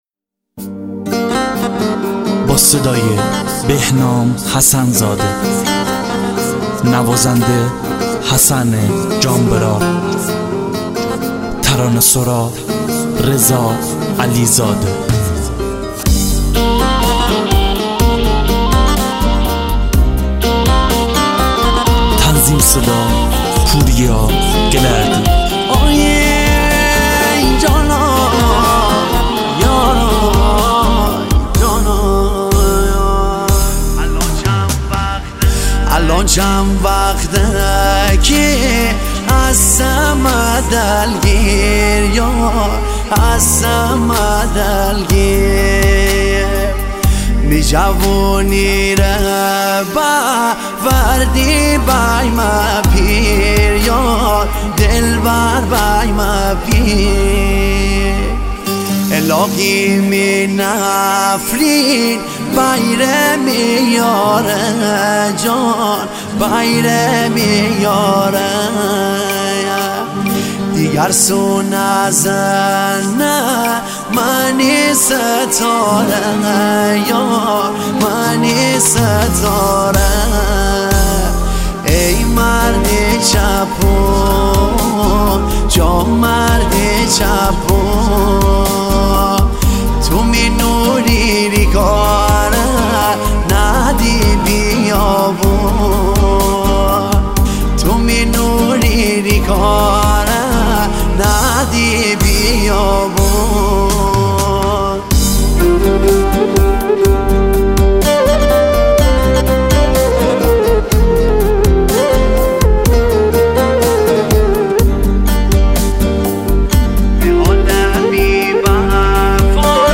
با ترکیب منحصربه‌فرد ملودی‌های سنتی و ترکیب‌های مدرن